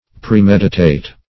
Premeditate \Pre*med"i*tate\, v. i.